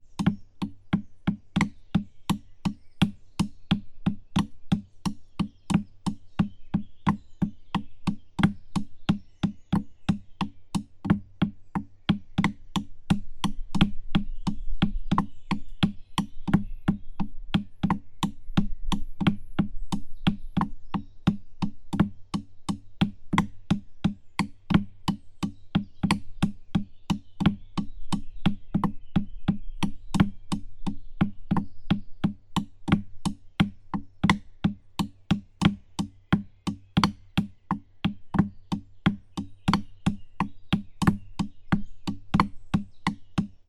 Paradiddle
Der Paradiddle ist eine Folge von gleichmäßigen Einzel- und Doppelschlägen.
Diese Paradiddle habe ich im Wald auf einem alten Baumstamm getrommelt.
paradiddle-baum-stick.mp3